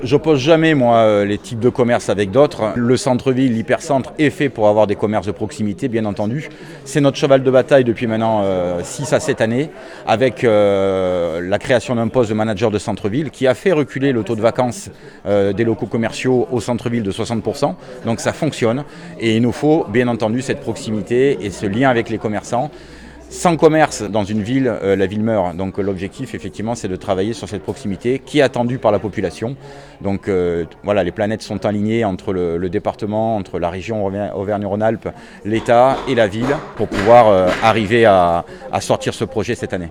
Le maire, Jean-Philippe Mas, se félicite de cette concrétisation.
ITG Jean-Philippe Mas 1 – Halles gourmandes (40’’)